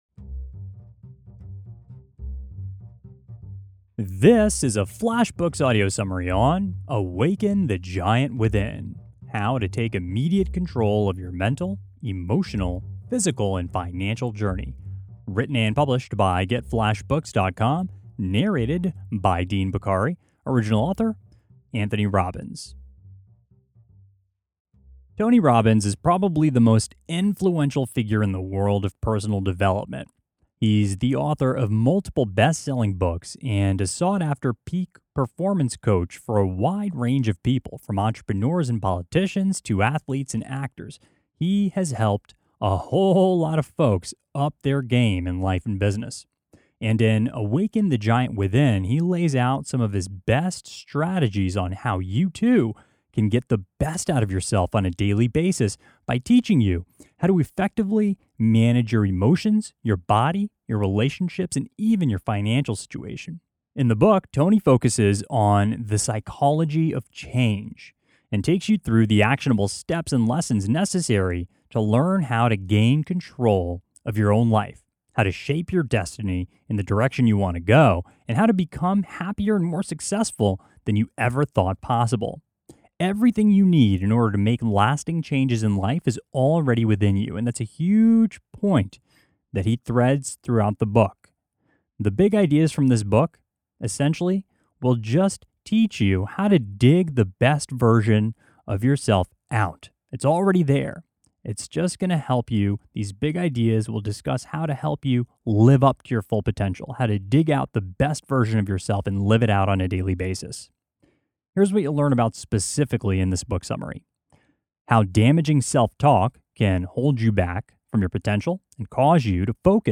awaken-giant-within-tony-robbins-audiobook-summary-mp3.mp3